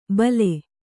♪ bale